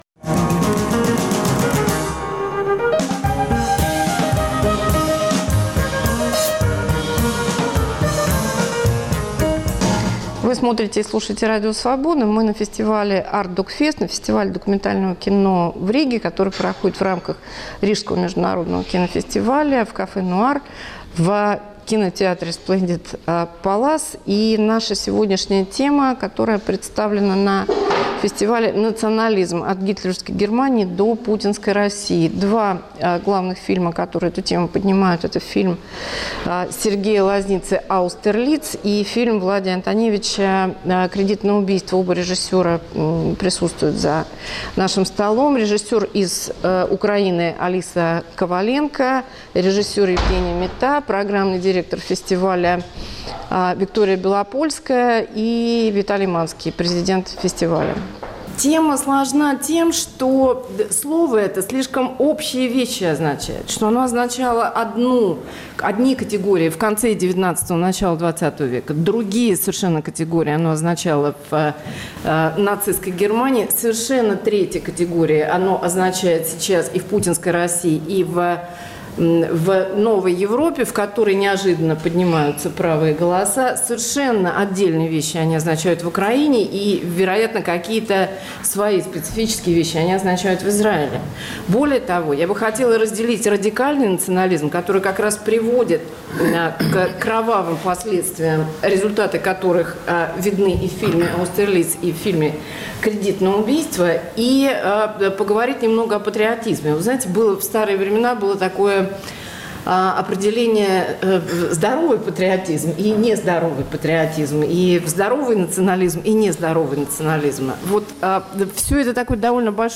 Дискуссия на “Артдокфесте”: национализм от гитлеровской Германии до путинской России. “Аустерлиц” и “Кредит на убийство”: фильм Сергея Лозницы о нацистских лагерях смерти и фильм Влади Антоневича о русских неонацистах, убийцах гастарбайтеров.